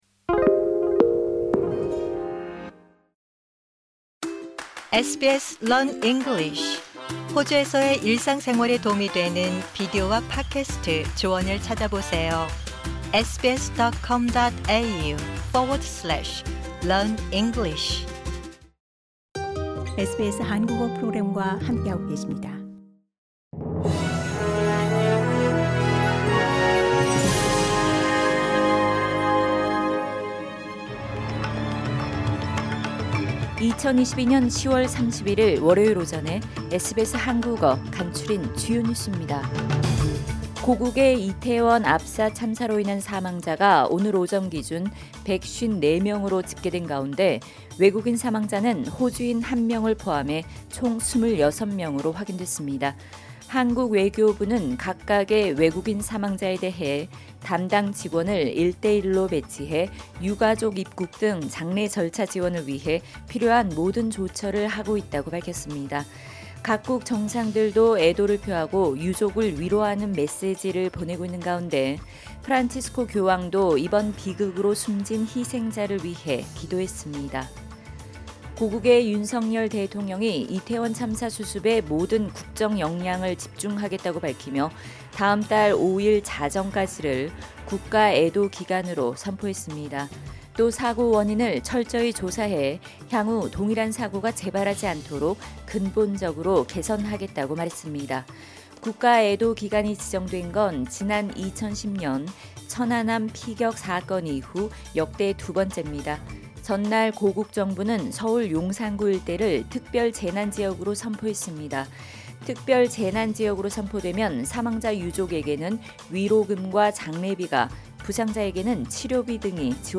2022년 10월 31일 월요일 아침 SBS 한국어 간추린 주요 뉴스입니다.